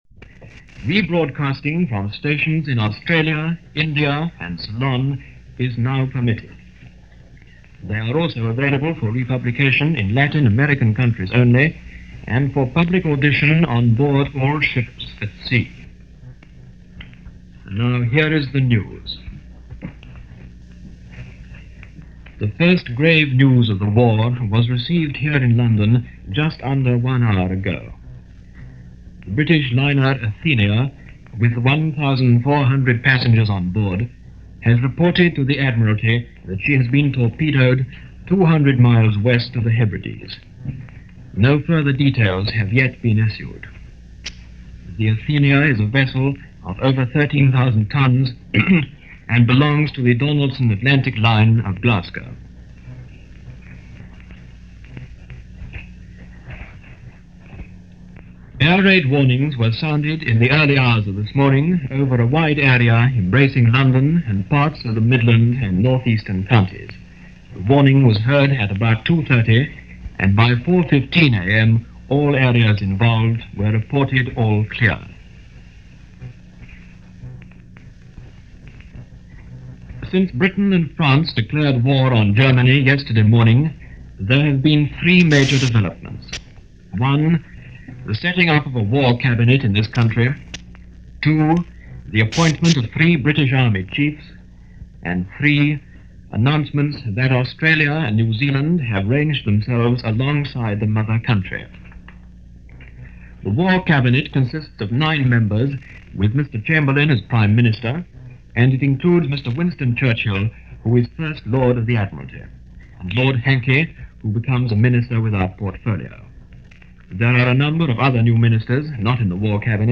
September 4, 1939 - Calling Reserves - Filling Sandbags - Europe At War - Situation in Poland since war began the day before, via BBC Home Service.
A recording of the announcement of War by the King from the previous day, was re-broadcast during this news summary. After the rebroadcast, the news continues with a summary of what was going on up to this point.